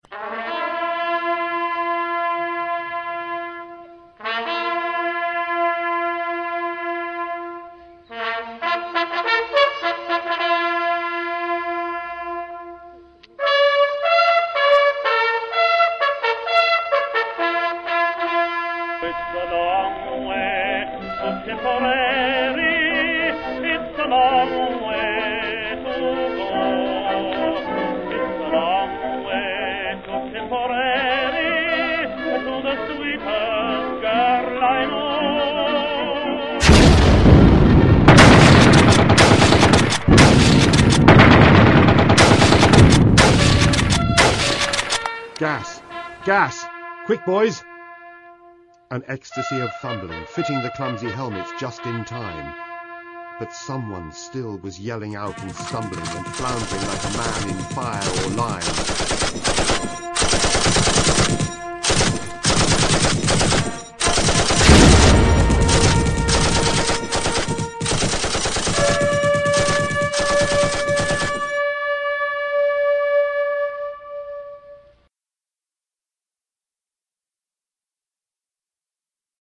A soundscape amalgamating several of the elements we have come to associate with The Great War. Over the background of the Last Post being played on bugle are a section from 'It's a Long Way to Tipperary' and a reading from'Dulce et Decorum Est', interspersed with machine gun, mortar fire and shell explosions.